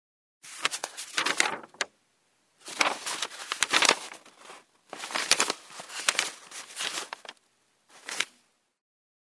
Звуки бумаги
Шорох перелистываемых страниц – вариант 2